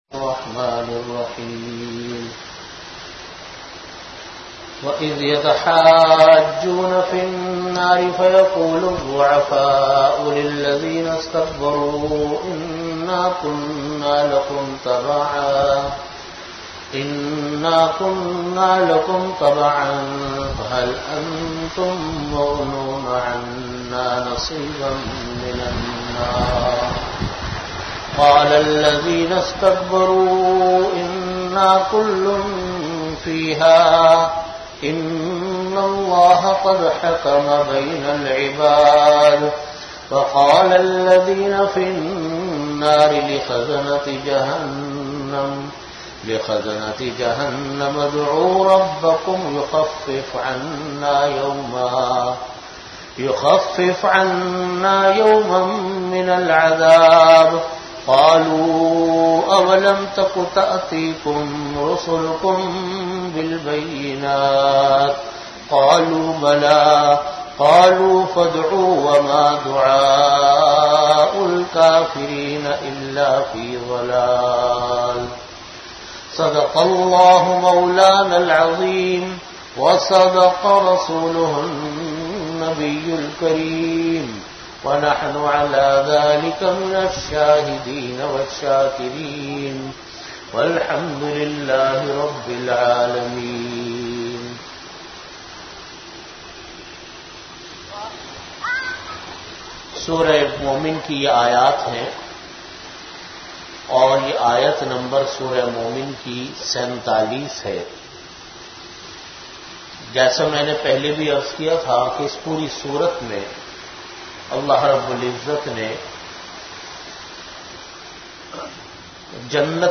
Tafseer Surah Mumin - 9 (Rasoolullah Ki Muhabbat Ka Taqaza)
Time: After Asar Prayer Venue: Jamia Masjid Bait-ul-Mukkaram, Karachi